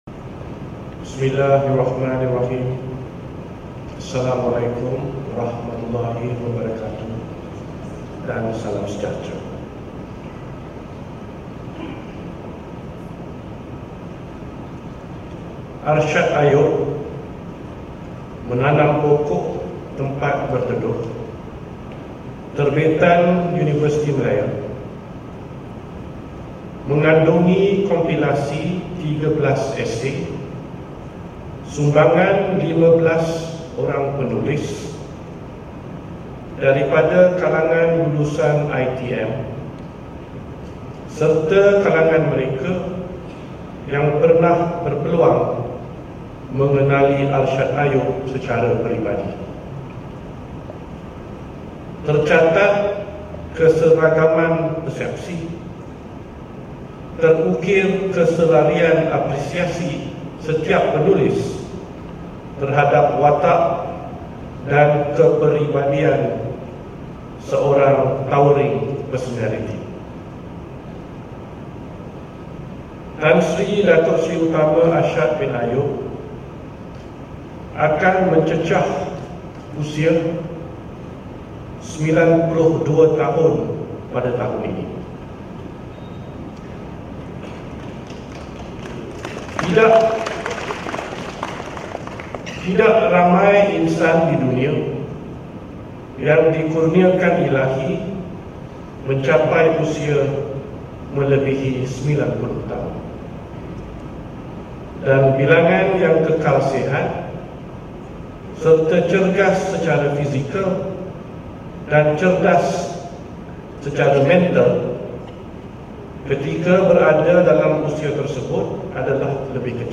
Ucapan Sultan Perak Sultan Nazrin Muizzuddin Shah semasa pelancaran buku: 'Arshad Ayub - Menanam Pokok Tempat Berteduh'